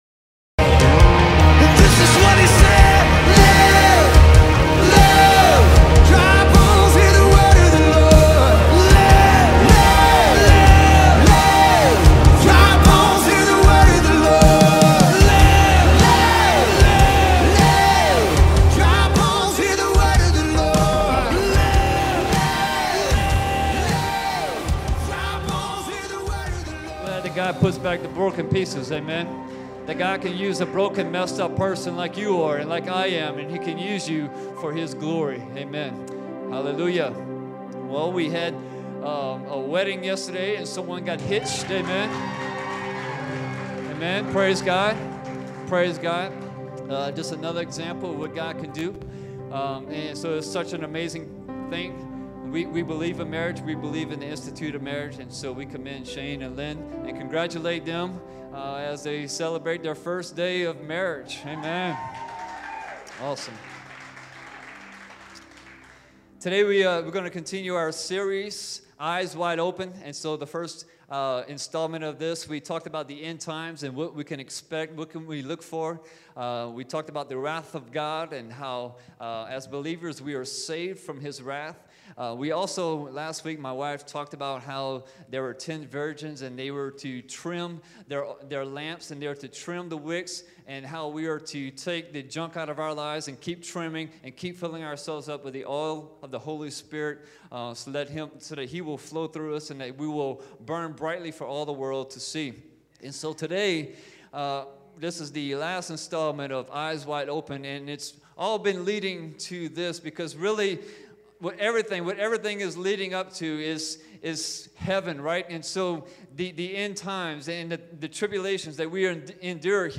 Current Sermon